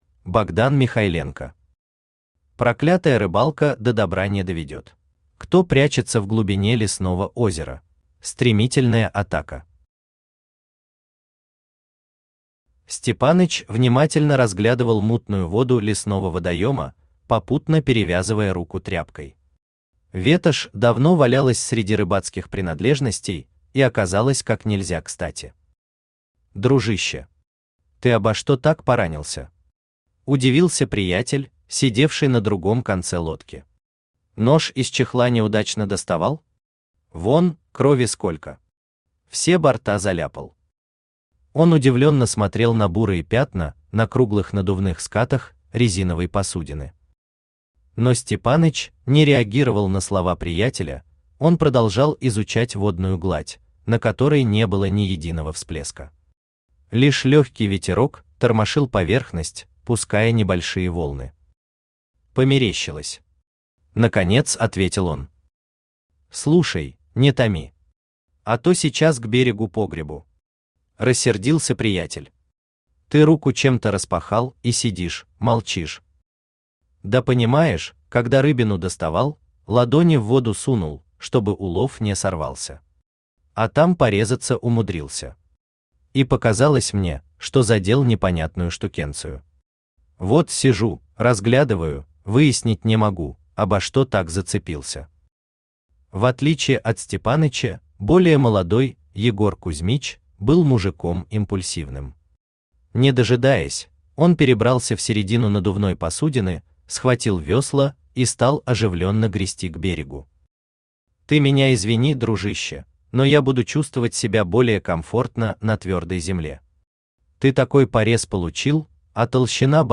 Aудиокнига Проклятая рыбалка до добра не доведет Автор Богдан Васильевич Михайленко Читает аудиокнигу Авточтец ЛитРес.